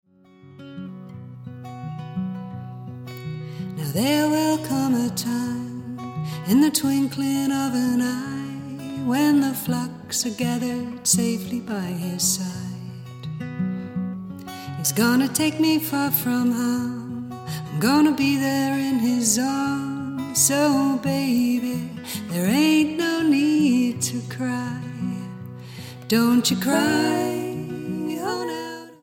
STYLE: Roots/Acoustic
gentle song of hope